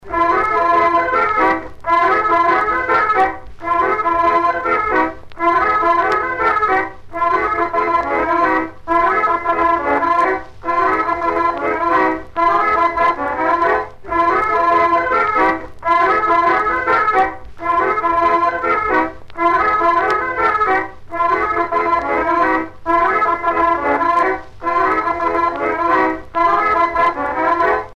Branle - 4
Marais Breton Vendéen
danse : branle : courante, maraîchine
Pièce musicale éditée